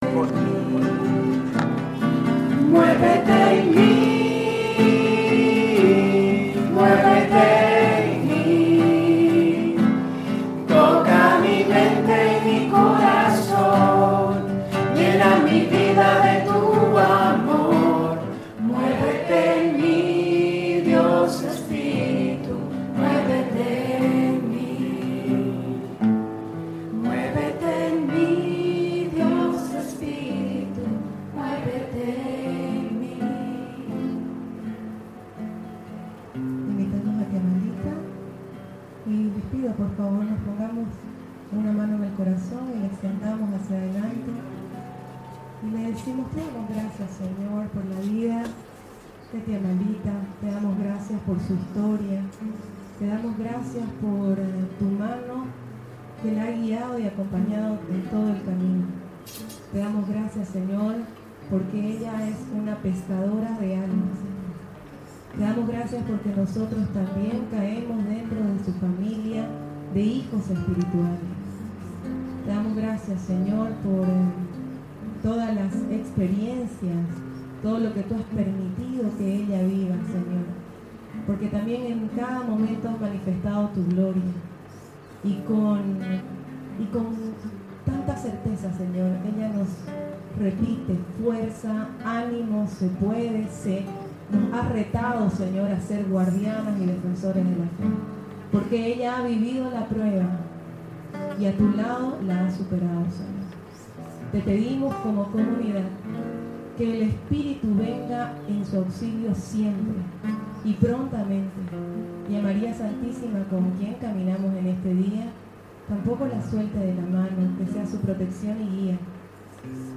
Retiro de Semana Santa 2018 organizado por los Guardianas y Defensores de la Fe con el apoyo de los hermanos Discípulos de Jesús de San Juan Bautista